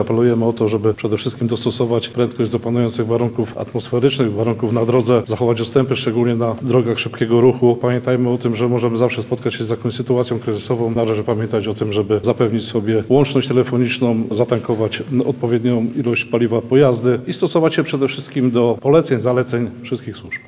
Wieczorem i w nocy w południowej części regionu pojawią się opady śniegu, dlatego o rozwagę do kierowców apeluje zastępca komendanta wojewódzkiego Policji w Lublinie, inspektor Olgierd Oleksiak.